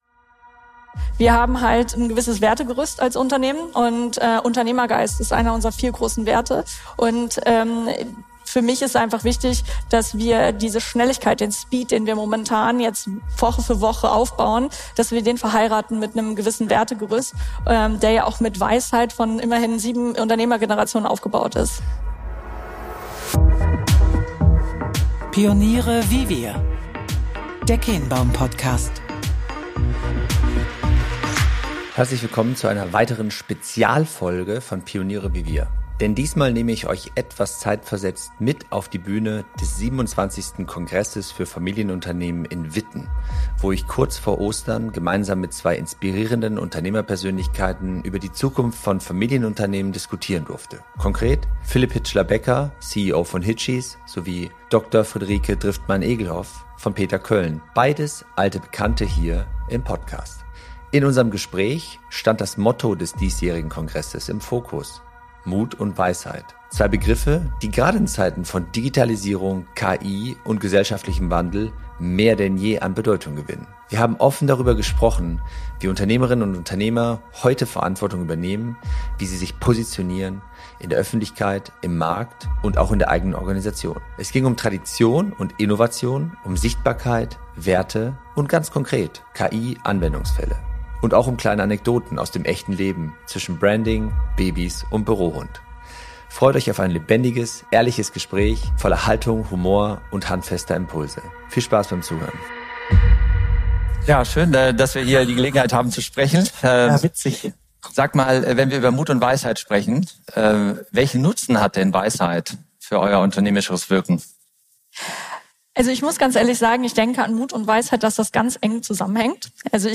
im Rahmen des 27. Kongresses für Familienunternehmer